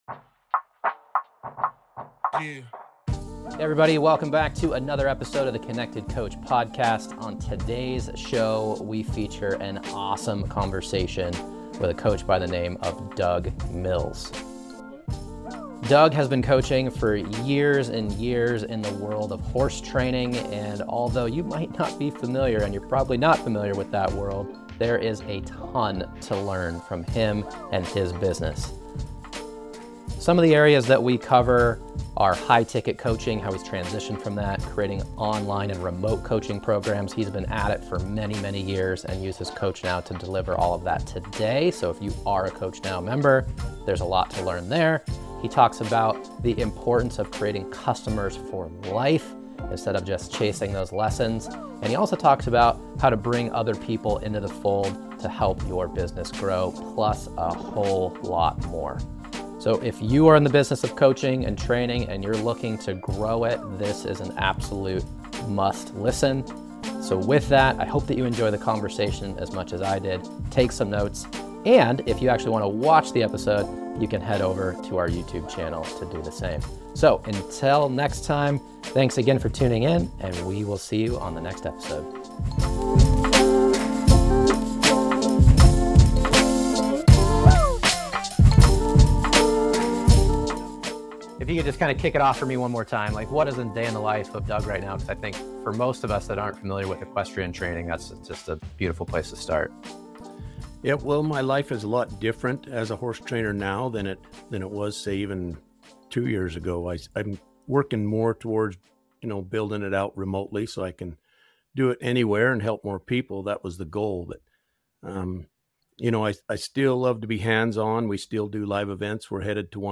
We found this conversation incredibly enlightening.